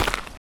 stepdirt_7.wav